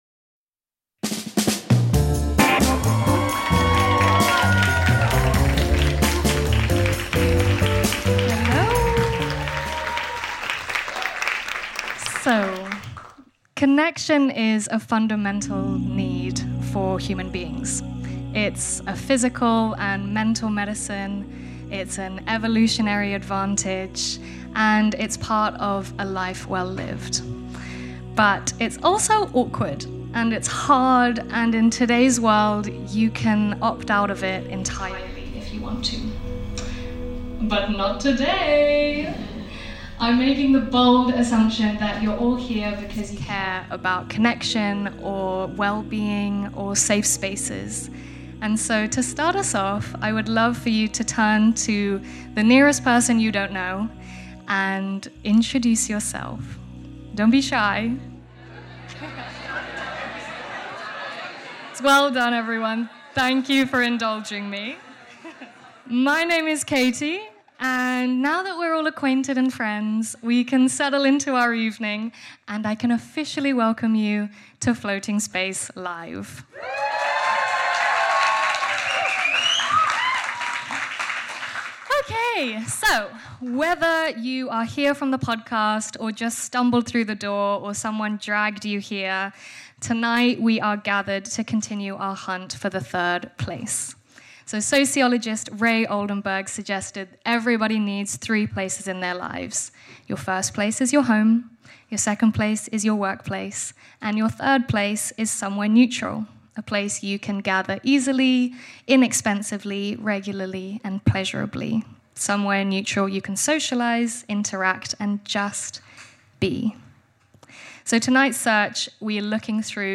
Floating Space: Live at the Roundhouse!
In part one of this special live recording, listen to never-before-heard clips from season one of this award-winning podcast.